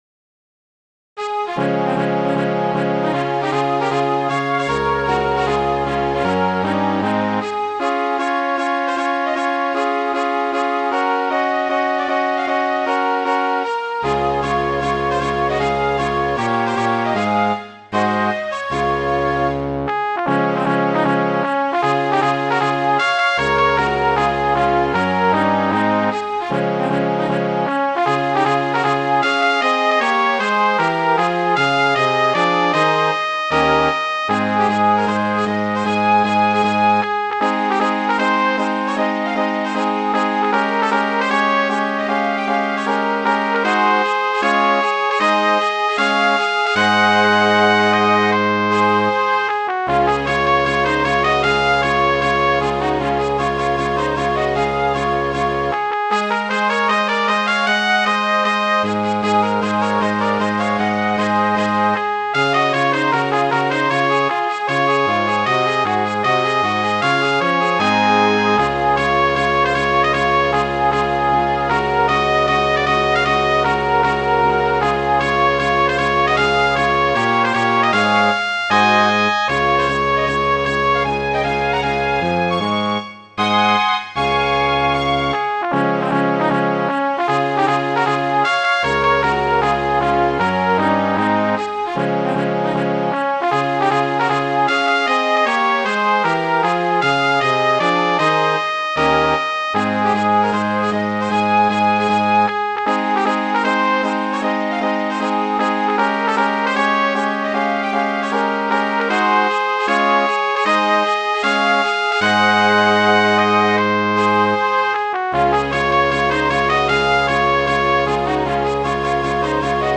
Midi File, Lyrics and Information to The Dashing White Sergeant